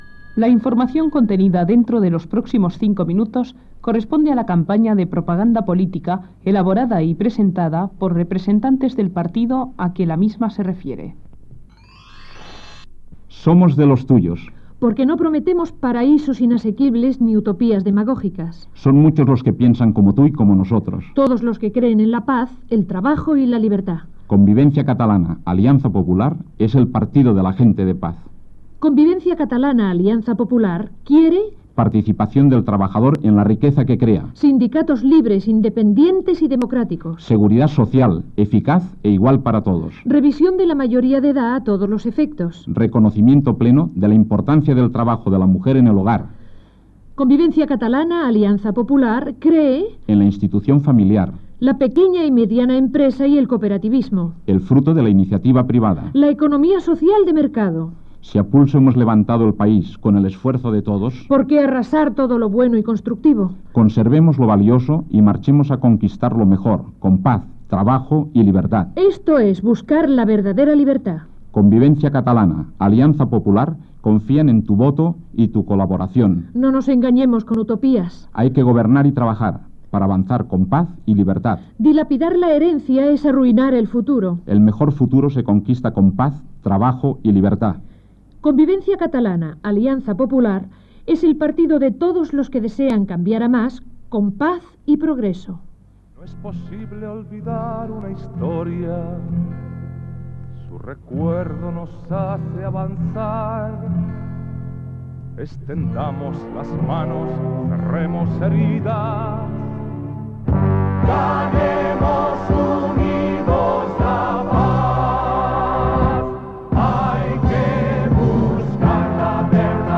Campanya de Propaganda Política: Convivencia Catalana -Alianza Popular